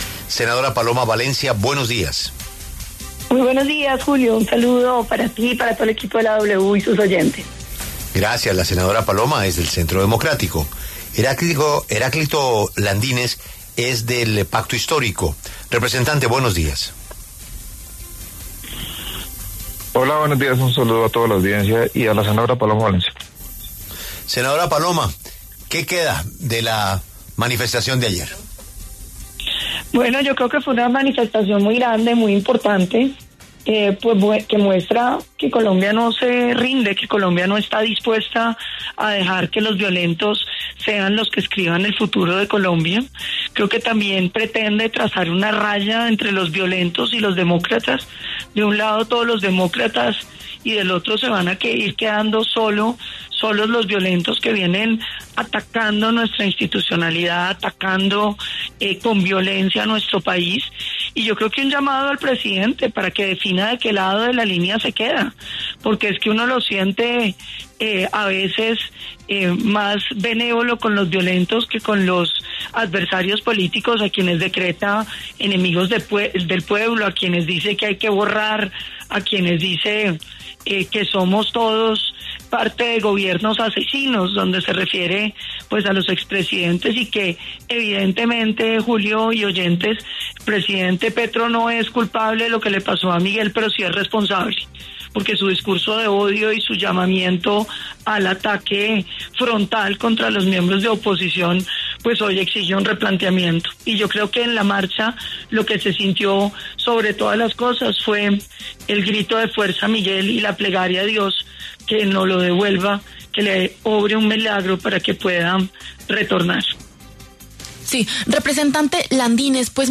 La senadora Paloma Valencia, del Centro Democrático, y el representante Heráclito Landinez, del Pacto Histórico, pasaron por los micrófonos de La W.